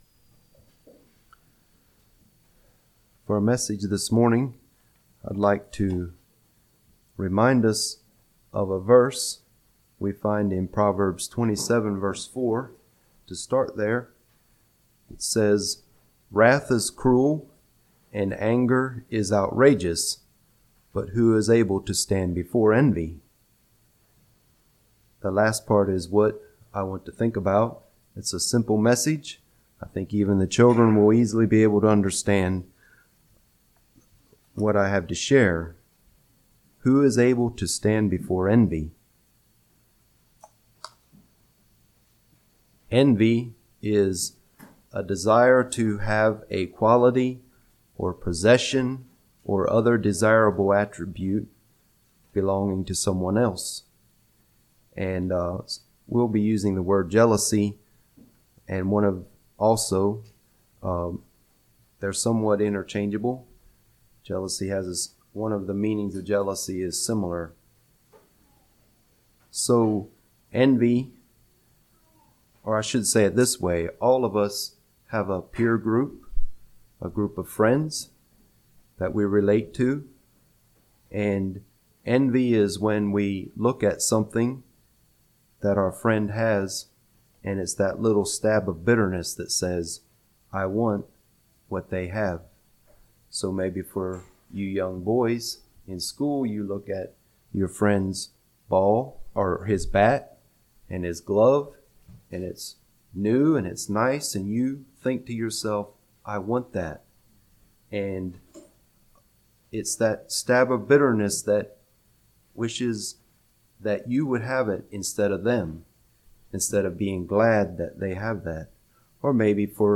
34:47 Summary: This sermon warns against envy and jealousy.